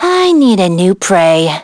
Yanne_L-Vox_Victory.wav